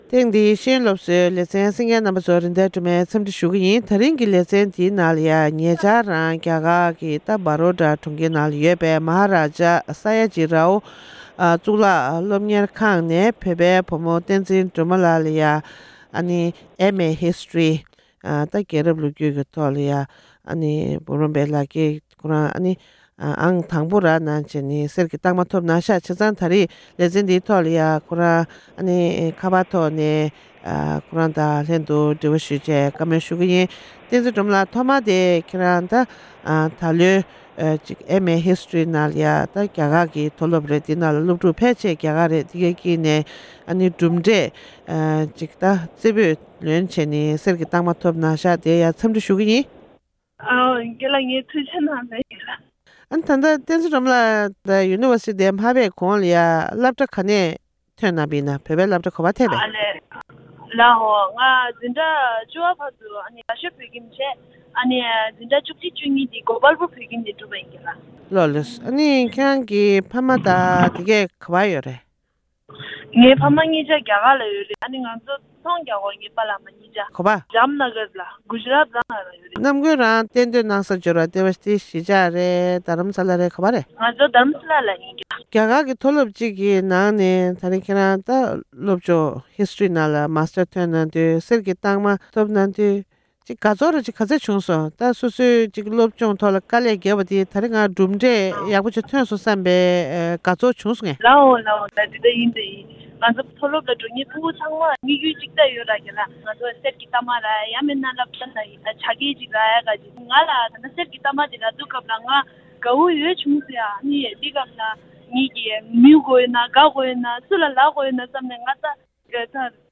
གནས་འདྲི་གནང་བ་ཞིག་གསན་རོགས་གནང༌༎